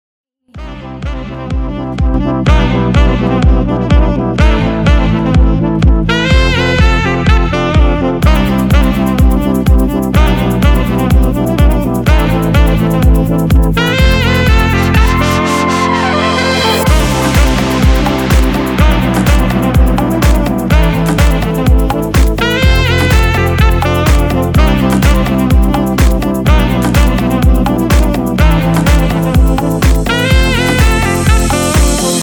• Качество: 256, Stereo
deep house
спокойные
без слов
Саксофон
Tropical
Тропики и саксофон...